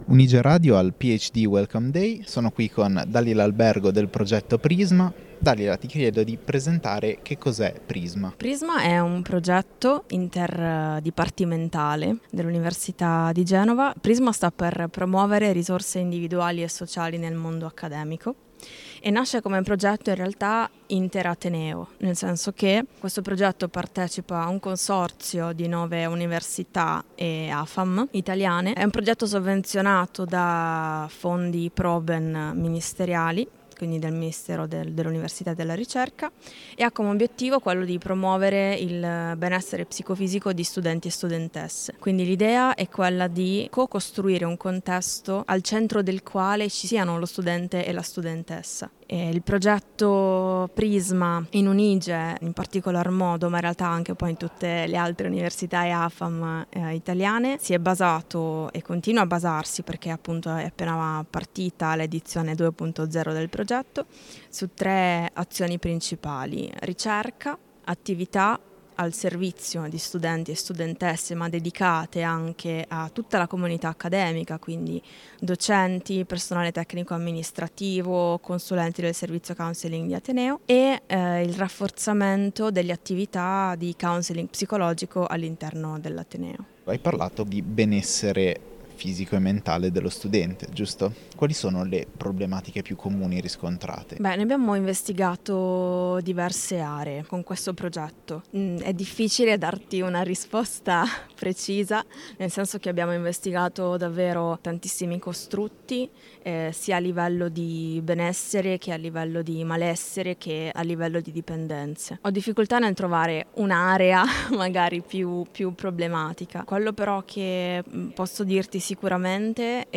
Un’intervista che mette al centro la persona, sottolineando il ruolo della ricerca e delle politiche universitarie nel promuovere il benessere.